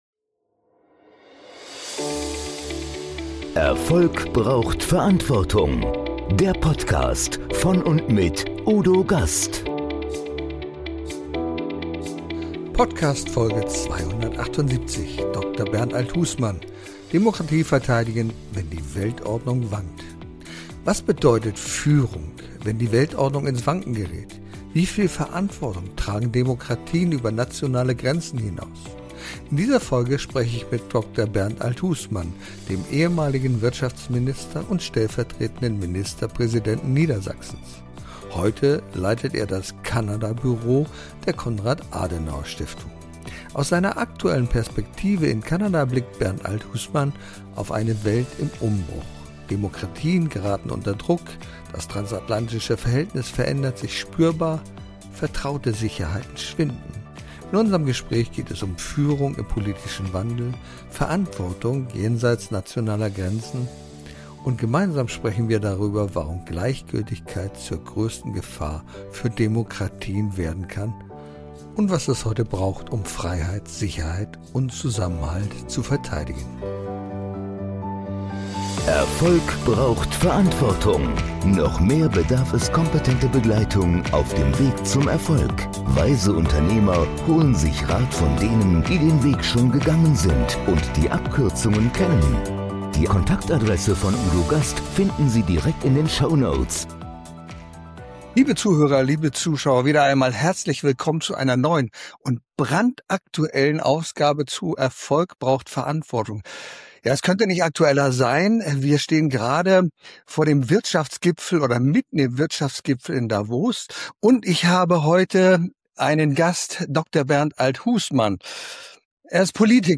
In dieser Folge spreche ich mit Dr. Bernd Althusmann, dem ehemaligem Wirtschaftsminister und stellvertretendem Ministerpräsidenten Niedersachsens.